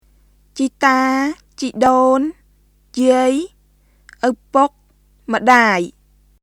[チー・ター、チー・ドーン／ジアイ、アウプック、ムダーイ　ciːtaː,　ciːdoːŋ(yiˑəi),　ʔəwpʊk,　mdaːi]